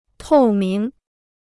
透明 (tòu míng): transparent; (fig.) transparent; open to scrutiny.